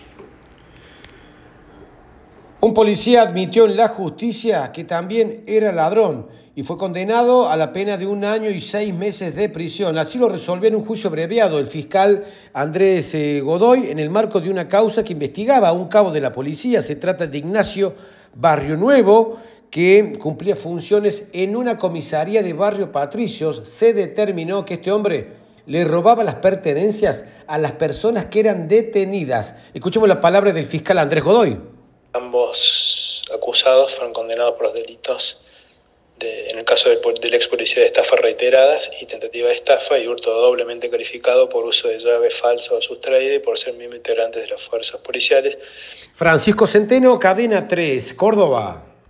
Condenaron a un policía de Córdoba que admitió que también era ladrón - Boletín informativo - Cadena 3 - Cadena 3 Argentina
Informe